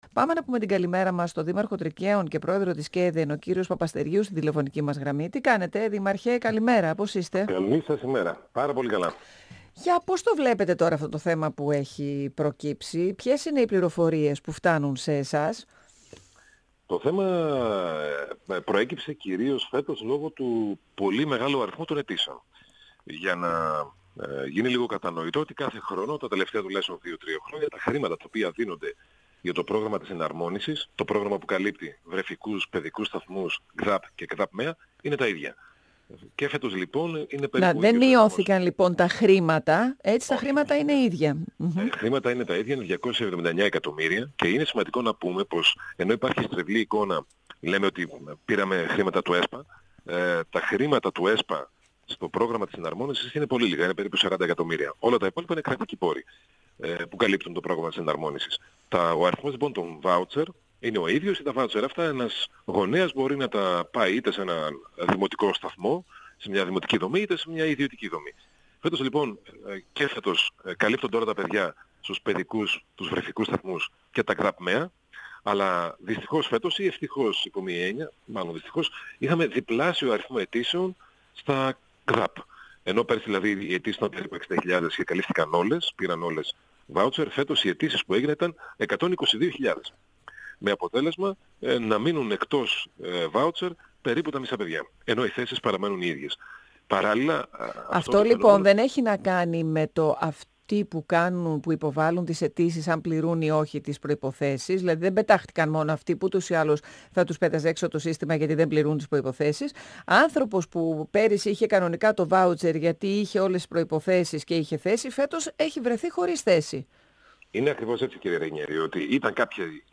Ο πρόεδρος της ΚΕΔΕ και Δήμαρχος Τρικκαίων Δημήτρης Παπαστεργίου, στον 102fm της ΕΡΤ-3.
Το ίδιο, μας είπε ο κ. Παπαστεργίου, πρέπει να ισχύσει και για τους εργαζόμενους, προκειμένου να μη χάσουν τη δουλειά τους. 102FM Συνεντεύξεις ΕΡΤ3